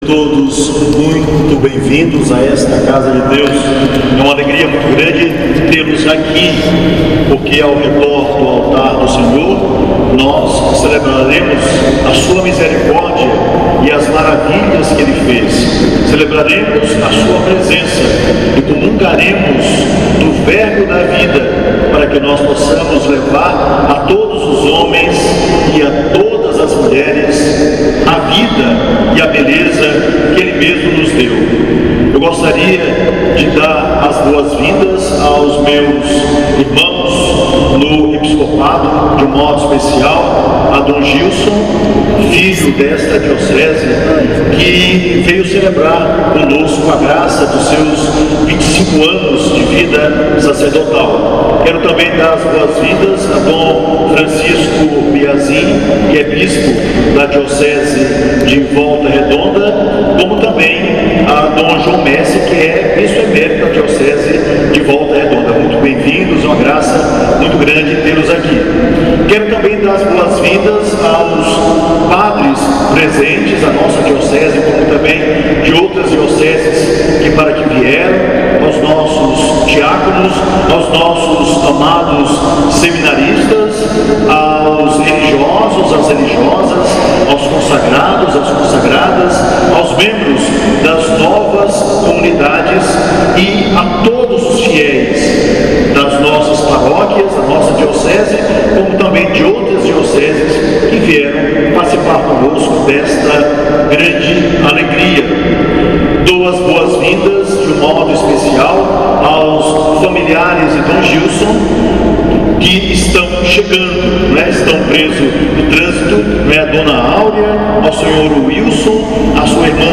No inicio da celebração, Dom Gregório Paixão lembrou a todos que em volta do altar do Senhor estarão celebrando a sua misericórdia e as maravilhas que Ele fez, assim como a sua presença e “comungaremos do verbo da vida para que possamos levar a todos os homens e mulheres a vida e a beleza que ele nos deu”. Dom Gregório Paixão falou da importância de se celebrar um jubileu, lembrando que sua origem vem da cultura judaica.